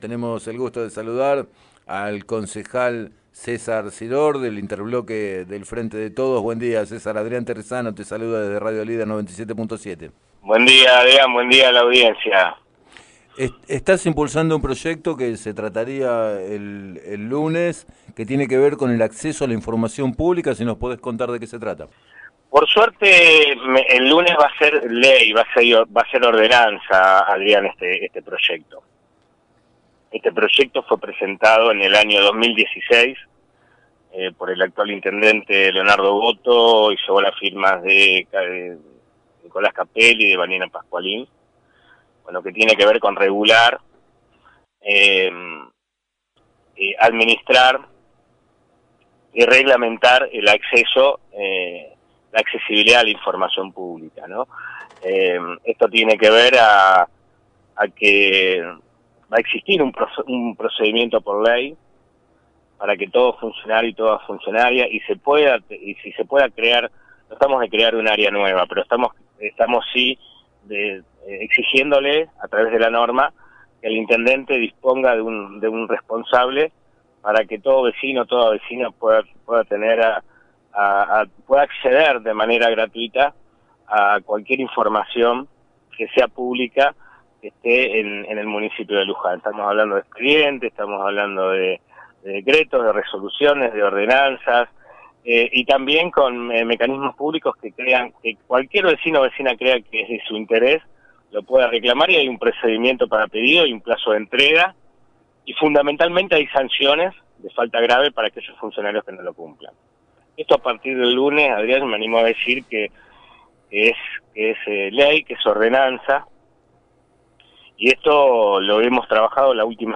El concejal César Siror, del Interbloque del Frente de Todos, explicó a Radio Líder 97.7 que hay acuerdo para la sanción de la ordenanza e indicó que cualquier vecino o vecina podrá solicitar información sin tener que explicar los motivos de su pedido.